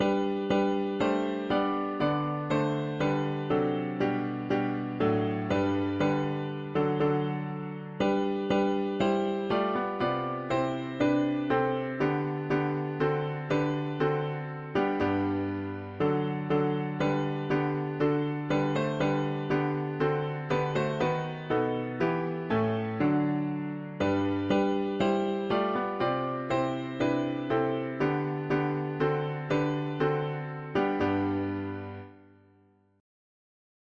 Melt the clouds of s… english christian 4part
A Alto volume adjust.
T Tenor volume adjust.
B Bass volume adjust.
Key: G major